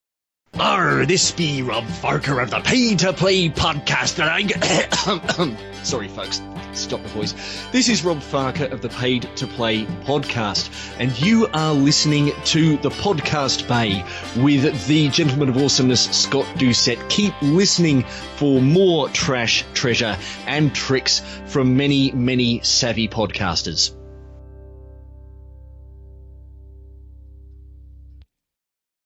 Commercial: Podcast Bay – Pirate Captain
Wanna hear me best Oirish Poirate voice?